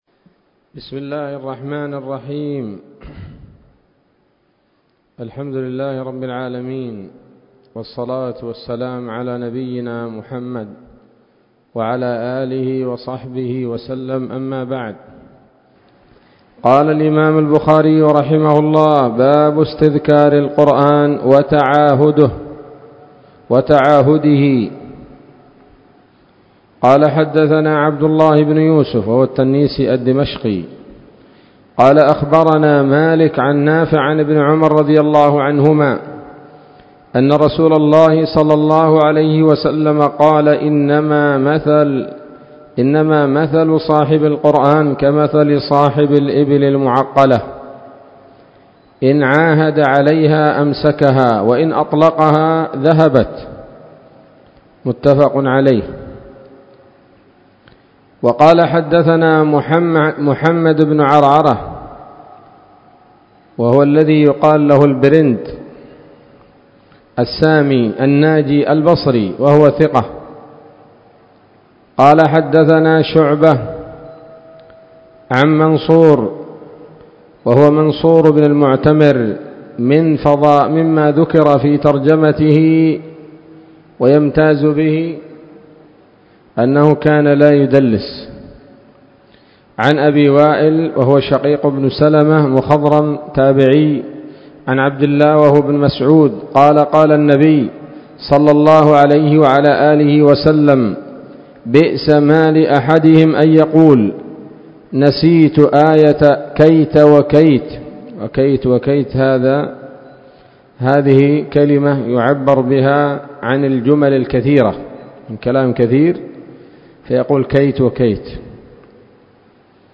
الدرس الرابع والعشرون من كتاب فضائل القرآن من صحيح الإمام البخاري